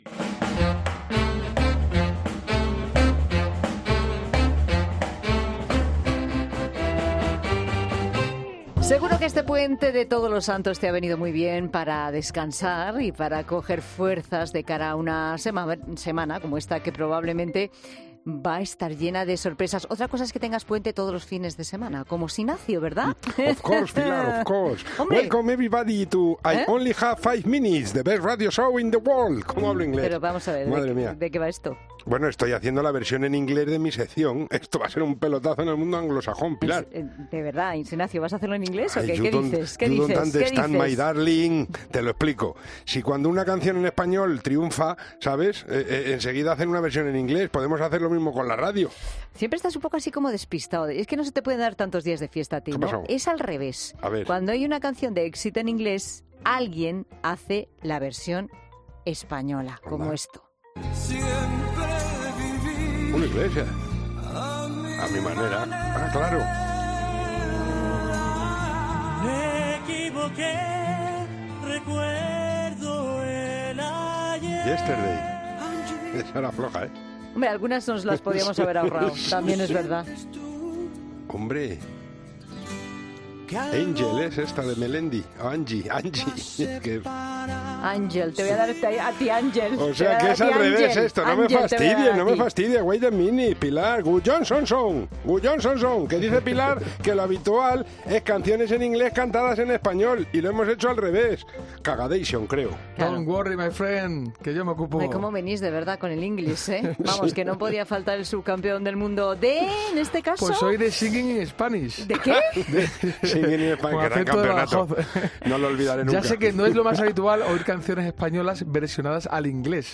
Y atentos a escuchar a Massiel o Salomé haciendo versiones internacionales de sus éxitos de Eurovisión.
También escucharemos a un grupo neozelandés versionando a Los Secretos, o una versión del tema más conocido de Celtas Cortos, y para terminar te pondremos un cover (al parecer es así como se dice versión en inglés) de la canción que ha tenido más éxito en la lista Billboard Hot 100.